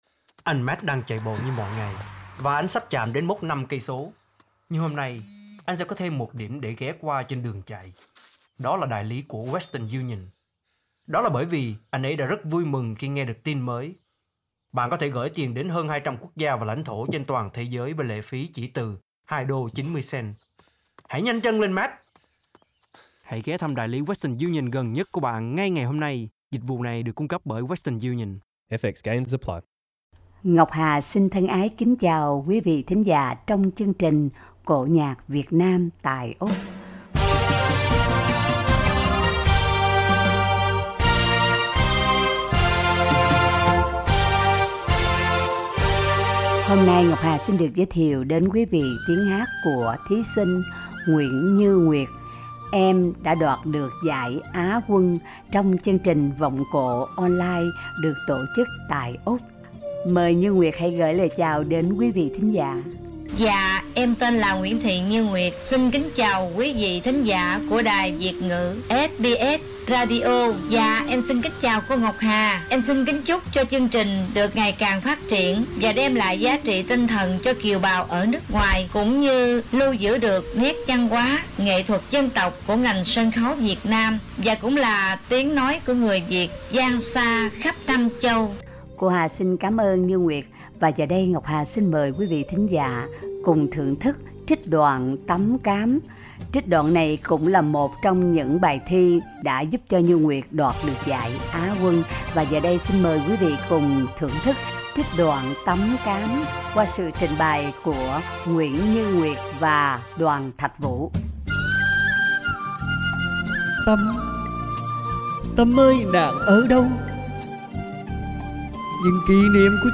Giọng ca Vọng cổ